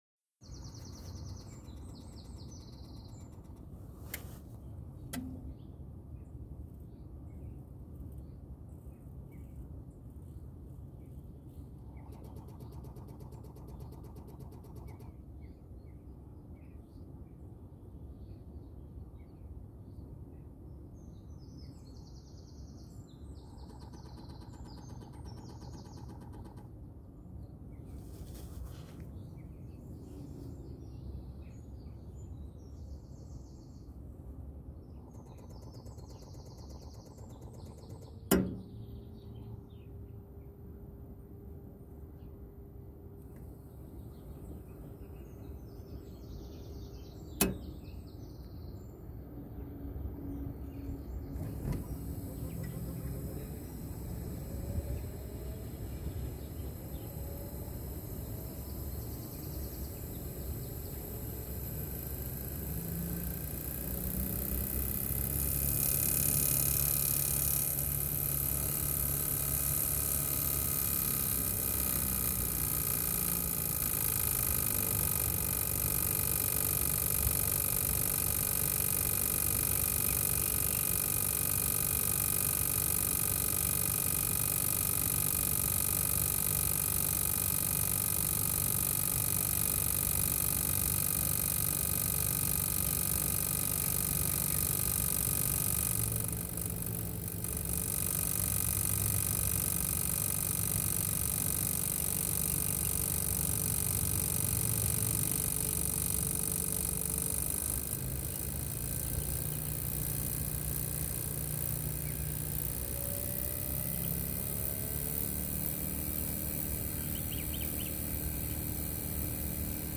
L'unité extérieure est installée sur une chaise avec des plots anti-vibratiles.
Mais celui-ci ne résout pas le problème de vibrations et nous avons également un bruit (comme une vieille voiture qui a du mal à démarrer) à chaque démarrage de l'UE.
Bruit compresseur PAC Mitsubishi
-bruit-compresseur-pac-mitsubishi.mp3